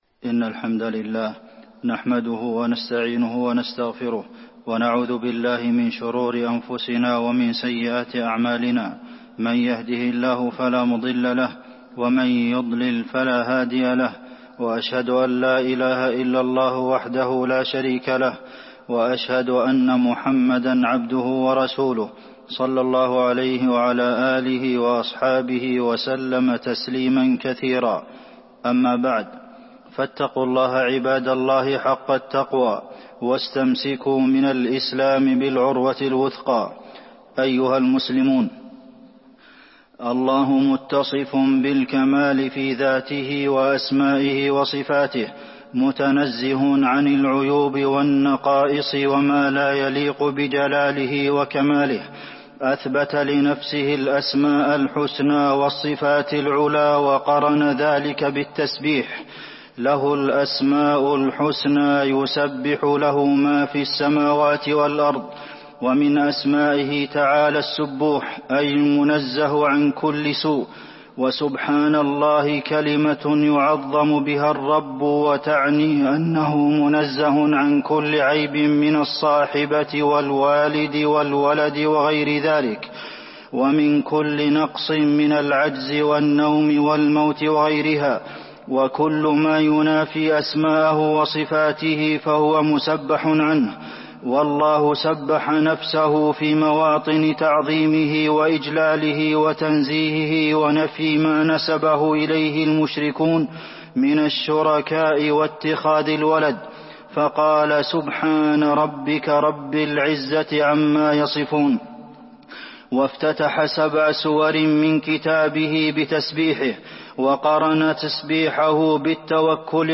تاريخ النشر ١٣ شعبان ١٤٤٢ هـ المكان: المسجد النبوي الشيخ: فضيلة الشيخ د. عبدالمحسن بن محمد القاسم فضيلة الشيخ د. عبدالمحسن بن محمد القاسم التسبيح